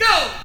VOX SHORTS-1 0017.wav